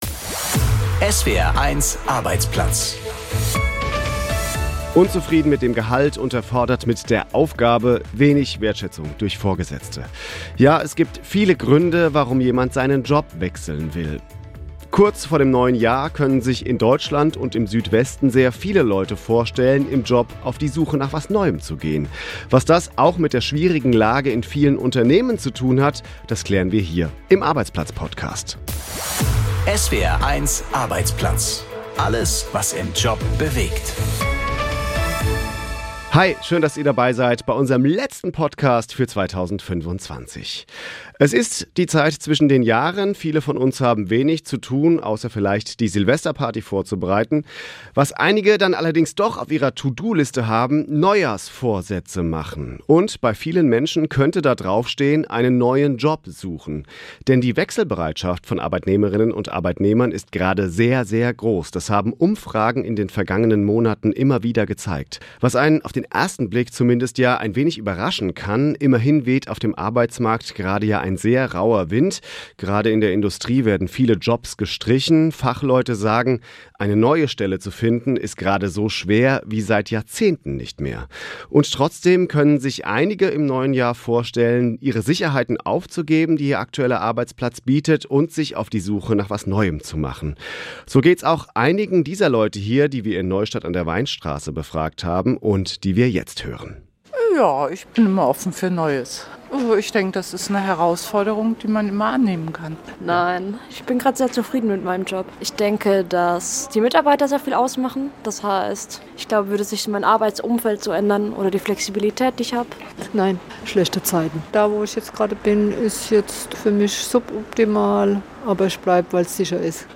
Das Radiomagazin für Arbeitnehmer und Arbeitgeber, für Betroffene und Beobachter, für Eltern und Lehrer, Auszubildende und Ausbilder. Reportagen aus Betrieben, Hintergründe, Meinungen, Urteile und Interviews.